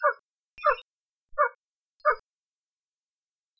patomedialuna.wav